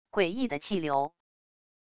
诡异的气流wav音频